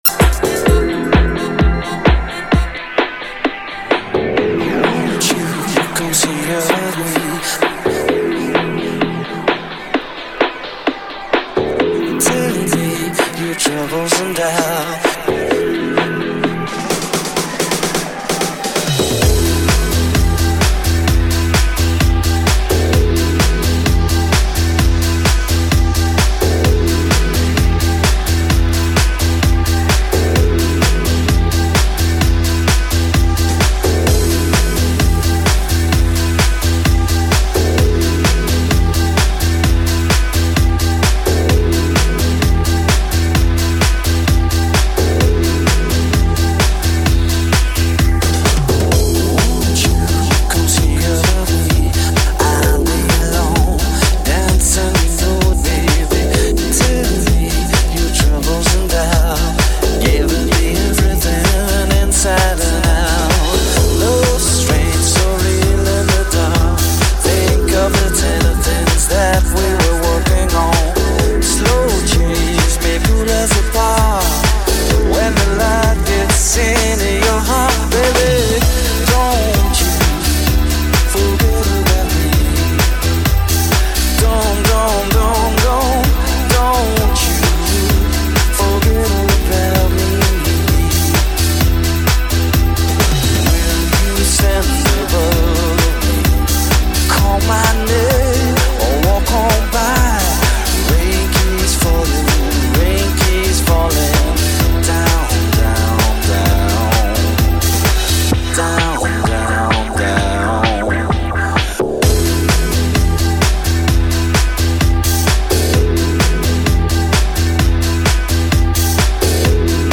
[13/11/2009]一首好听的英文单曲，感觉吉他弹得不错，问一下歌曲名？